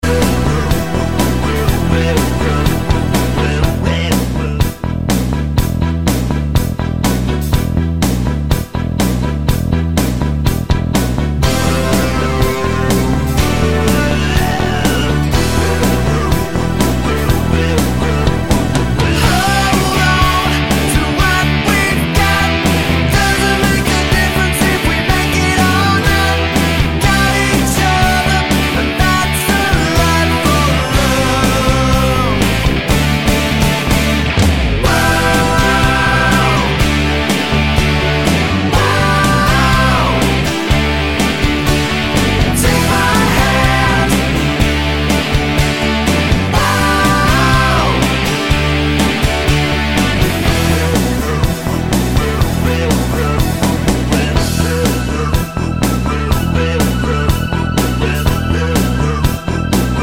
Twofers Medley Medleys 5:12 Buy £1.50